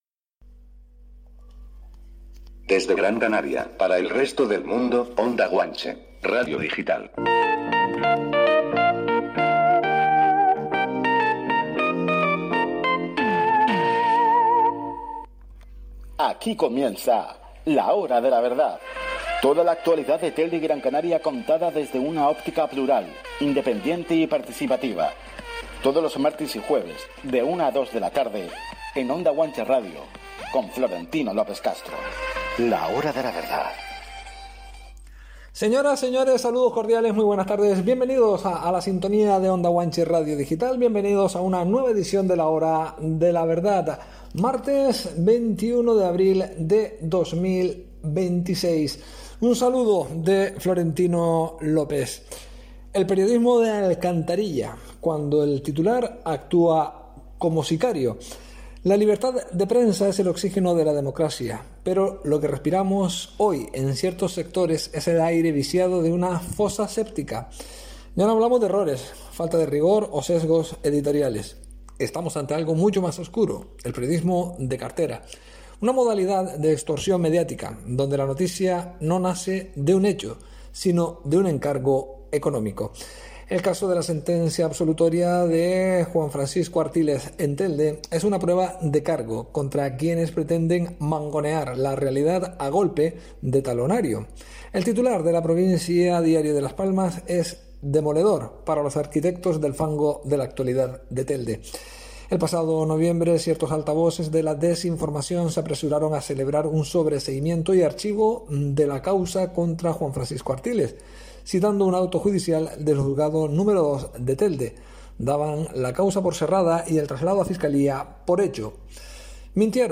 en el programa de radio de Onda Guanche «La hora de la verdad»